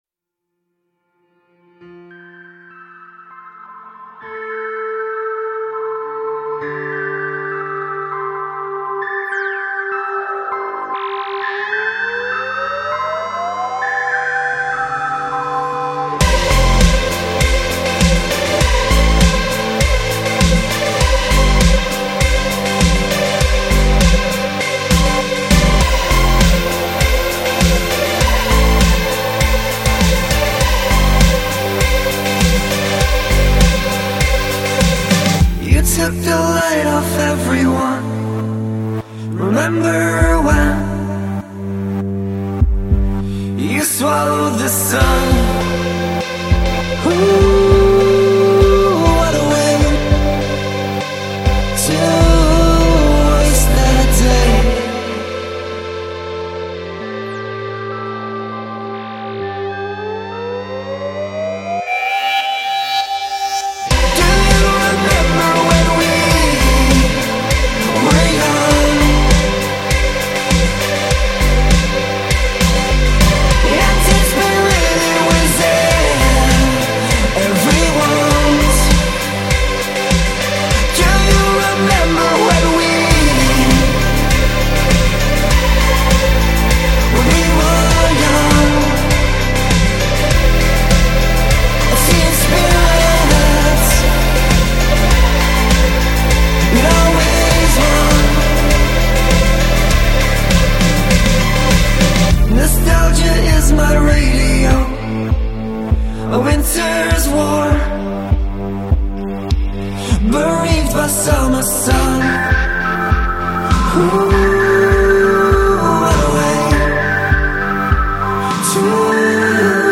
alt indie/electro band